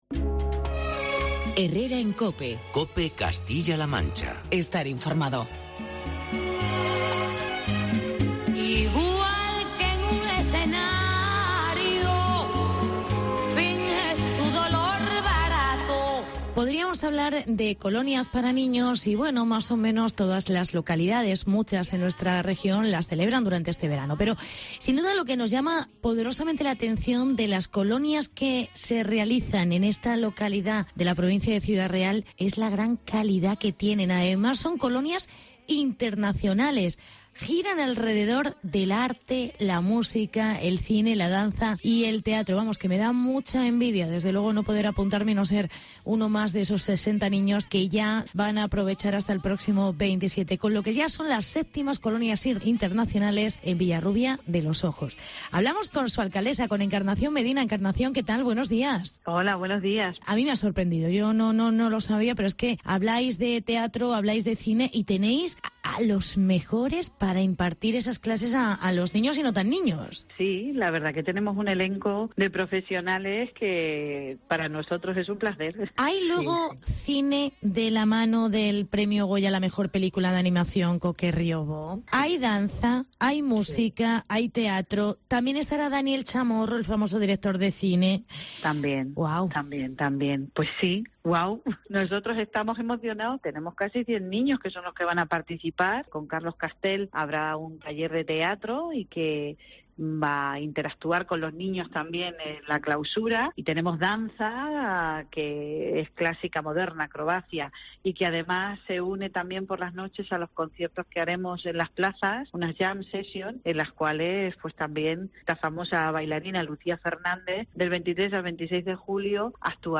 Entrevista con la alcaldesa: Encarnación Medina